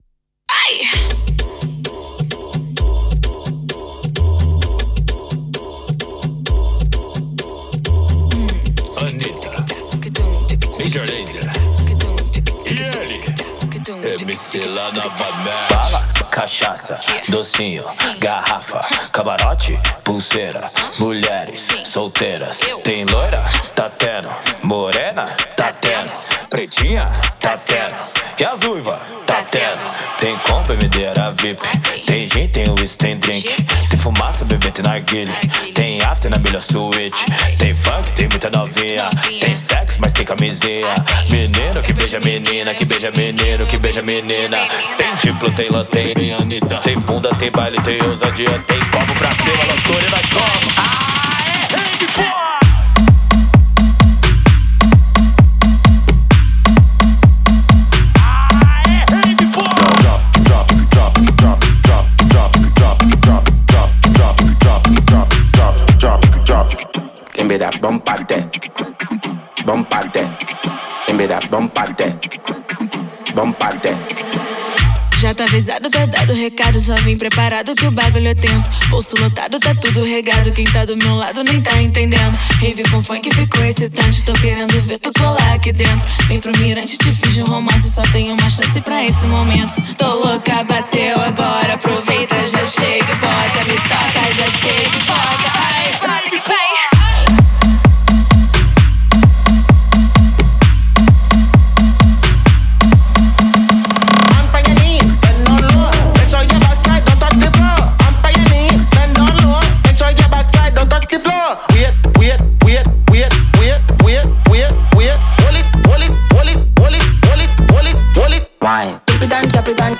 это зажигательный трек в жанре бразильского фанк-музыки